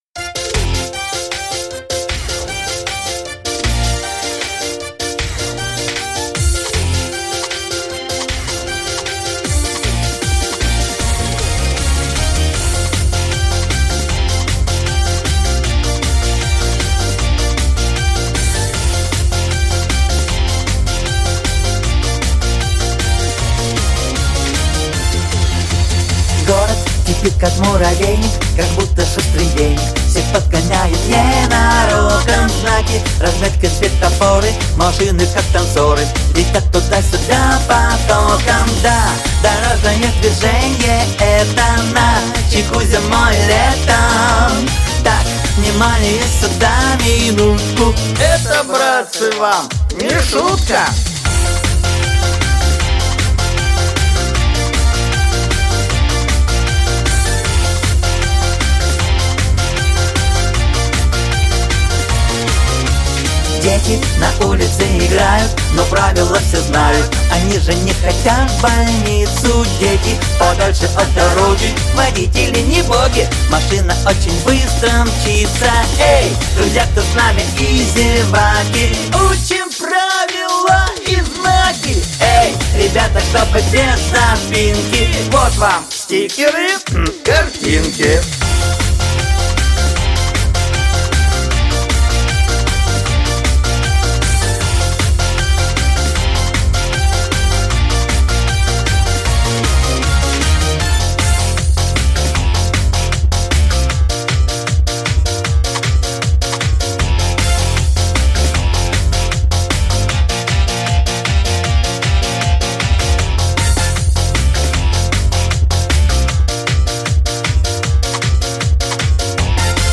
Монотонный гул дорожного движения с множеством машин